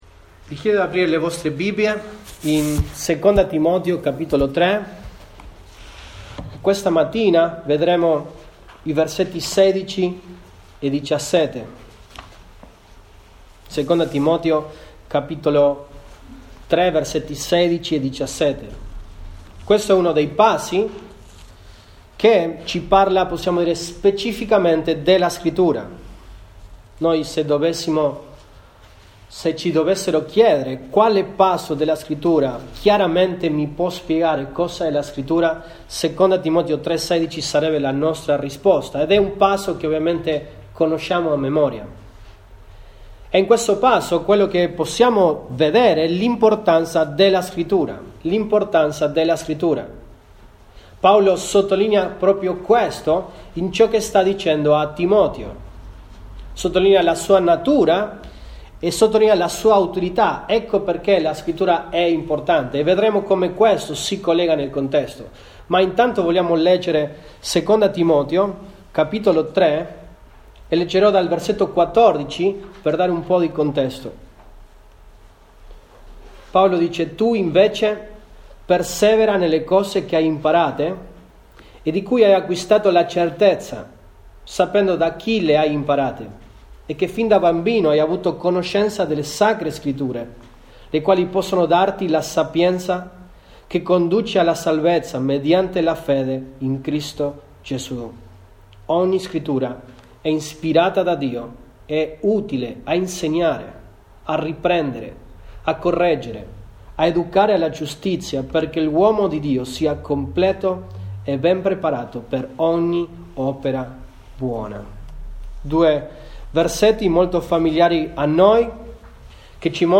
Giu 14, 2020 L’importanza della Scrittura MP3 Note Sermoni in questa serie L'importanza della Scrittura.